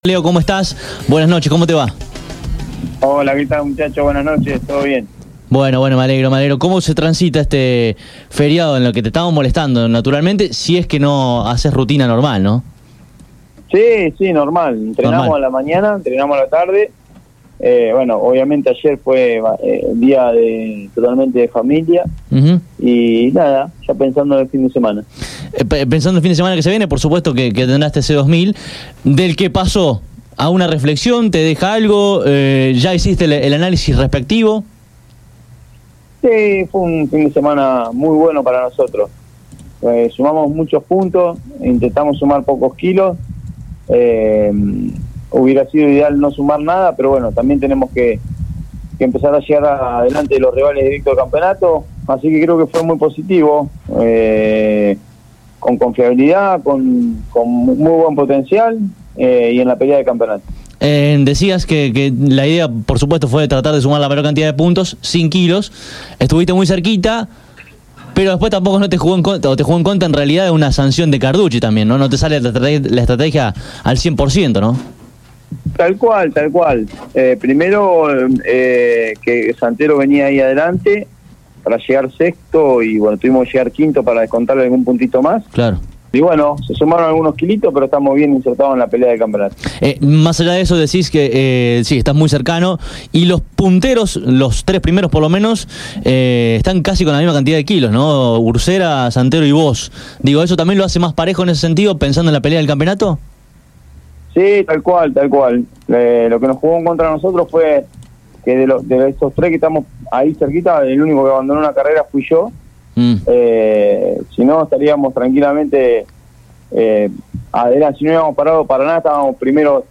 Leonel Pernía dialogó con CÓRDOBA COMPETICIÓN luego del fin de semana de Turismo Nacional en Posadas, Misiones.